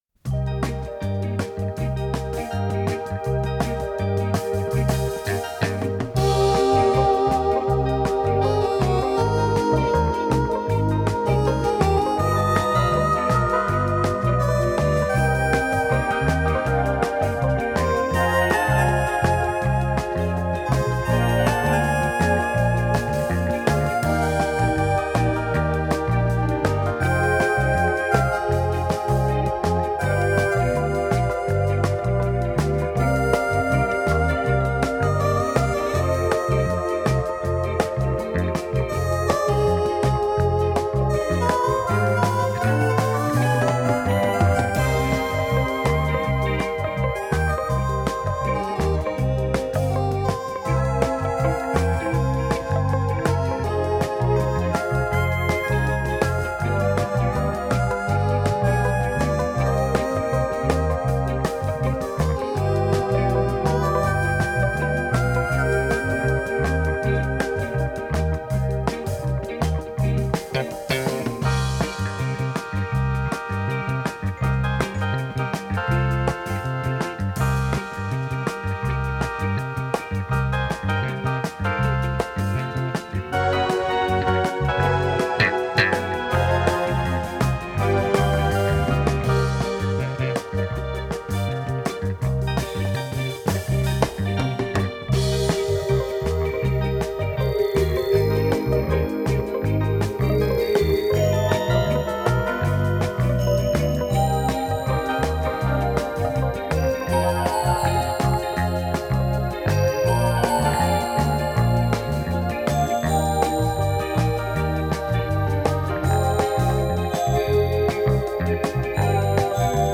с профессиональной магнитной ленты